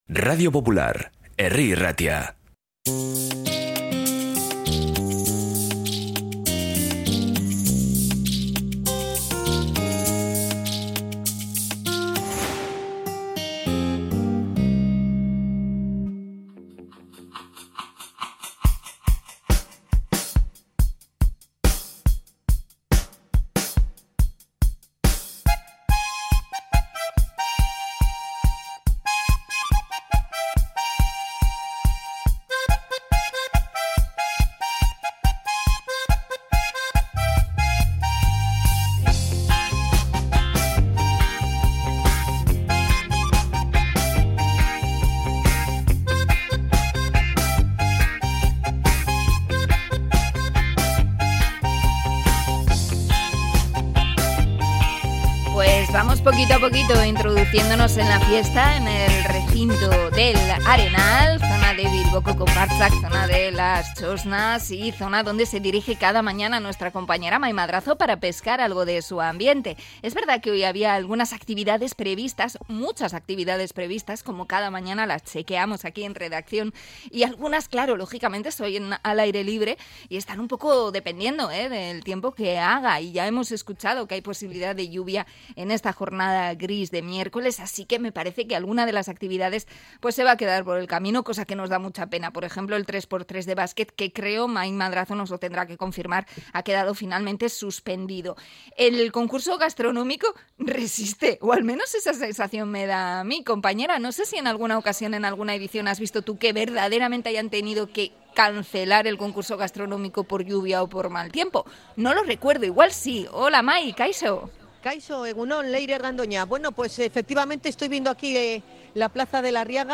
Conexión desde el concurso gastronómico: hoy, txipirones
Cebollita pochándose, muerte a la minipimer y txipis recién pescados: así suena el Arenal esta mañana